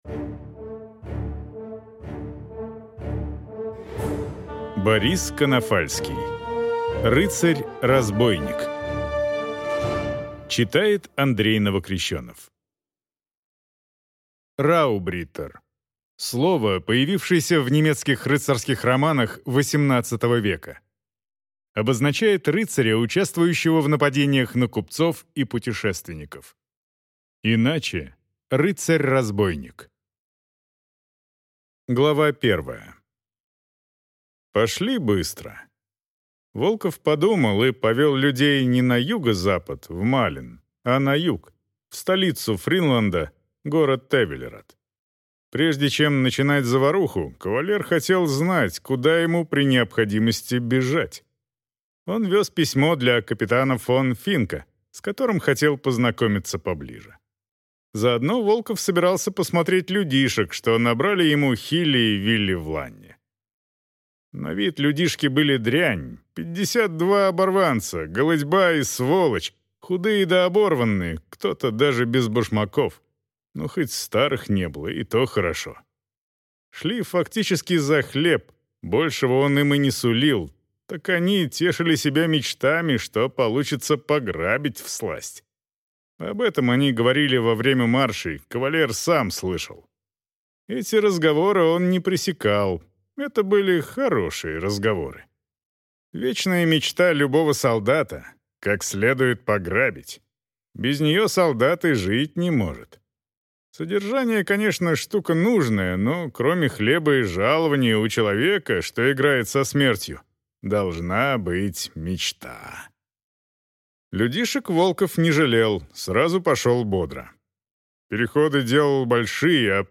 Аудиокнига Рыцарь-разбойник | Библиотека аудиокниг